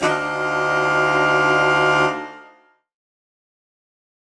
UC_HornSwellAlt_Dmajminb6.wav